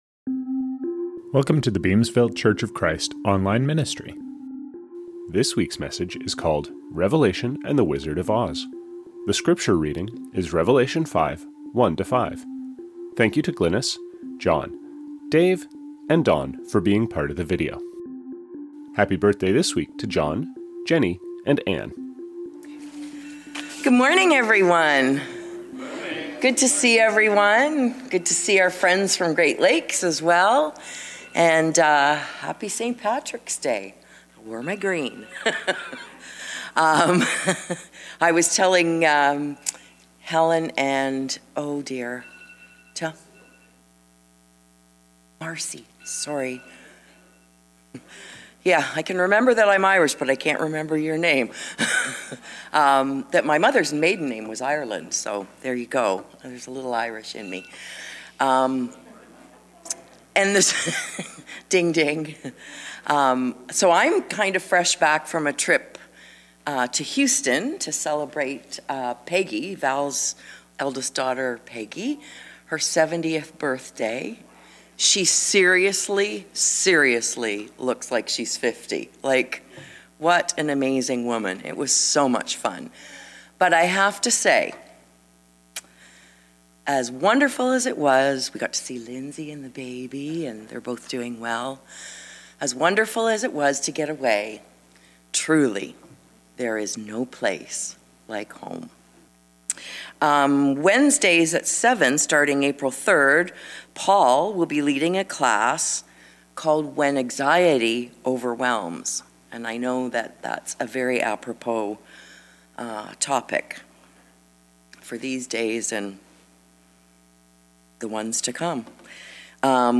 Scriptures from this service: Communion - Matthew 11:25-29 (RSV); 12:46-50 (RSV). Reading - Revelation 5:1-5 (NIV).